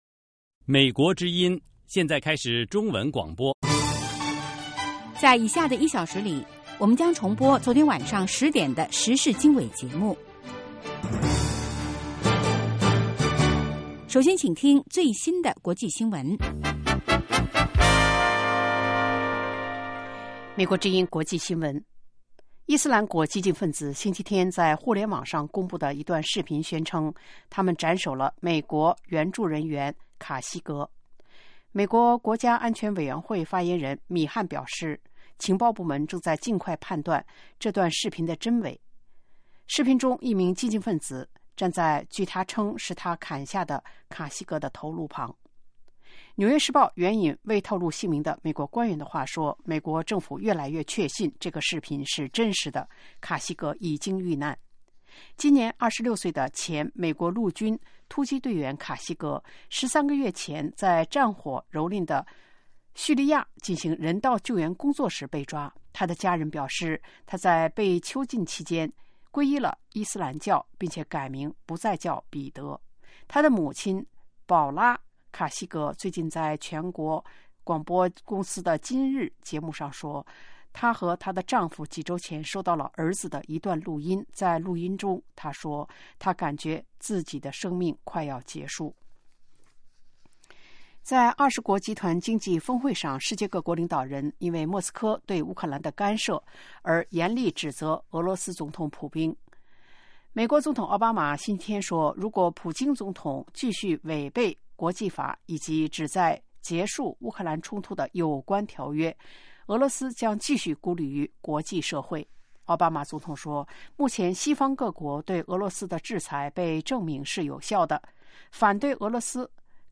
早6-7点广播节目